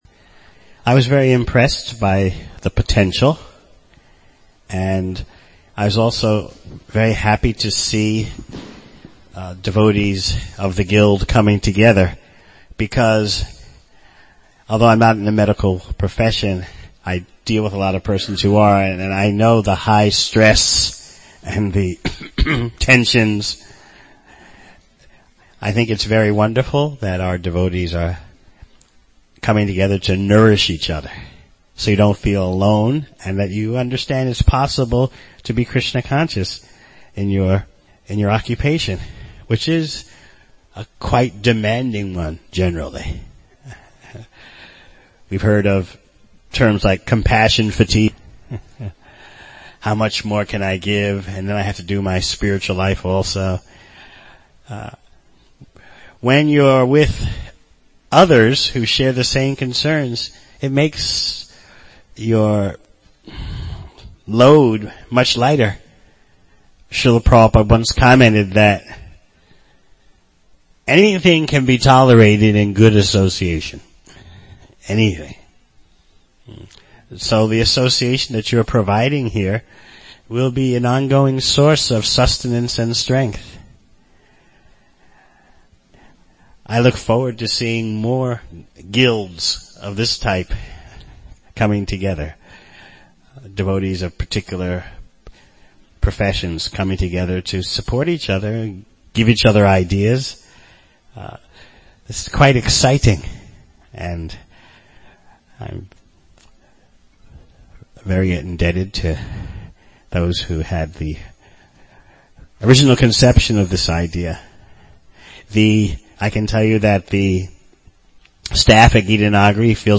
NA Physicians Retreat